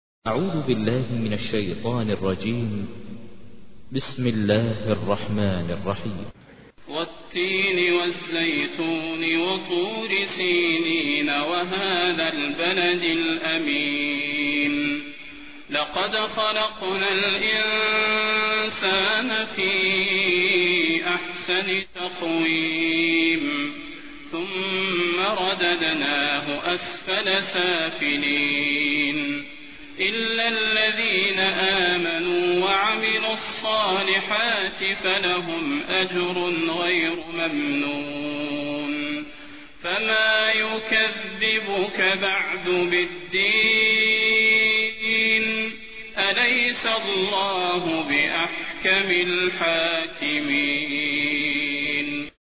تحميل : 95. سورة التين / القارئ ماهر المعيقلي / القرآن الكريم / موقع يا حسين